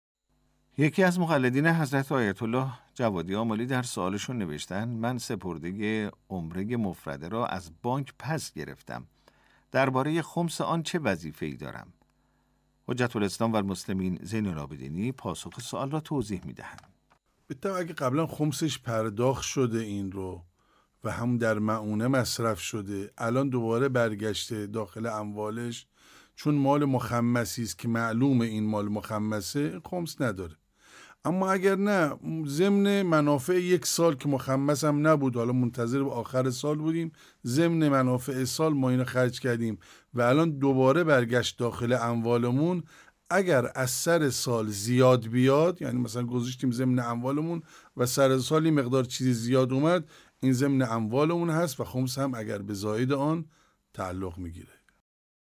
پاسخ نماینده دفتر حضرت آیت الله العظمی جوادی آملی